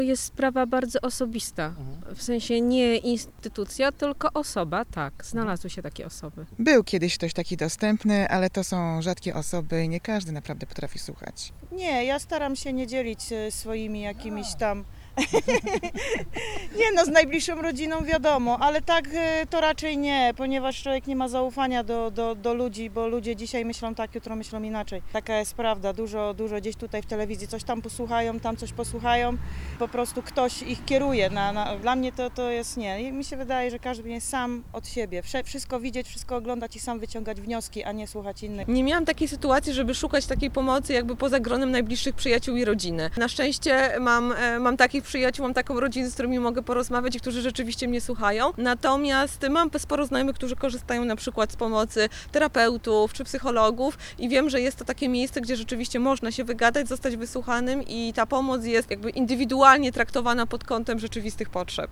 Przy okazji Światowego Dnia Słuchania zapytaliśmy mieszkańców Wrocławia i osoby odwiedzające nasze miasto o opinię nt. tego, co może wpływać na zamykanie się na rozmowę z innym człowiekiem: